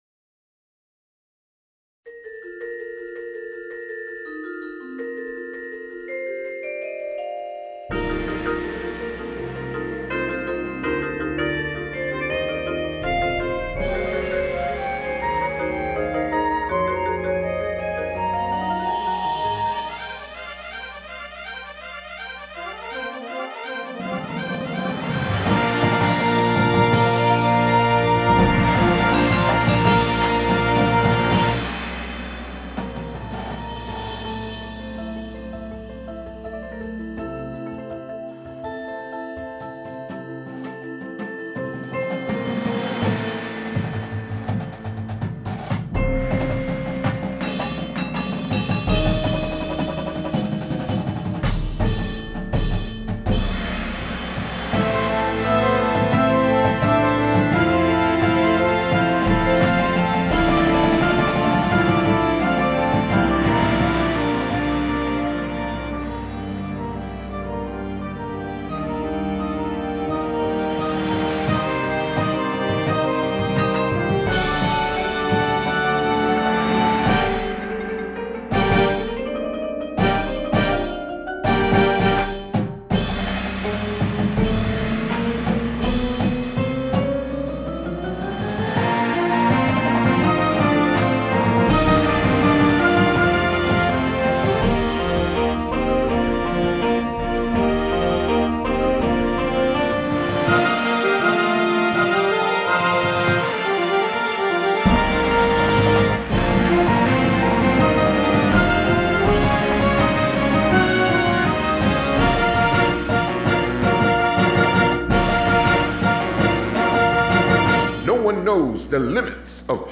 • Flute
• Alto Sax 1, 2
• Trumpet 1
• Trombone 1, 2
• Tuba
• Snare Drum
• Synthesizer – Two parts
• Marimba – Two parts